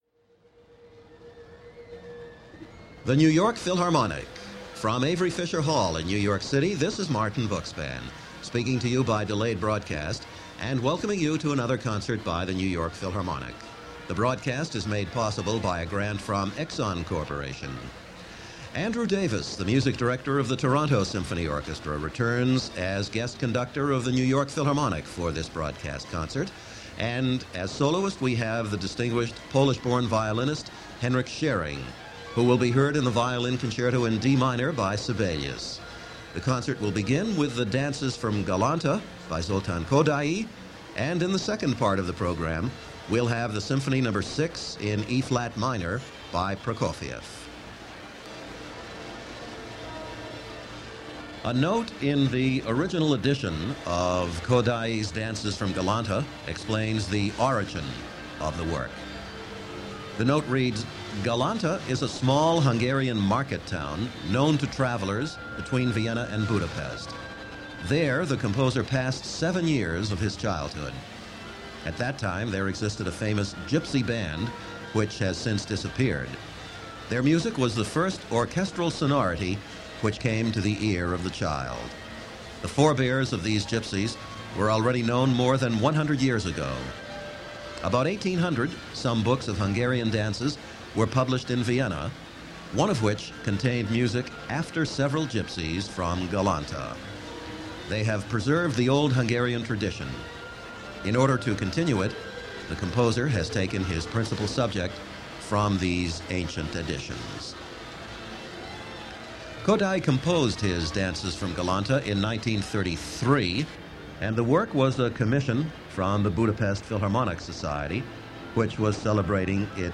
The New York Philharmonic – Andrew Davis, guest Conductor – Henryk Szeryng, violin – March 15, 1978 – Gordon Skene Sound Collection –
Another historic concert this week – legendary violinist Henryk Szeryng returning to the New York Philharmonic after a six year absence to perform with Toronto Symphony Music Director Andrew Davis, guest conducting in this March 15, 1978 broadcast.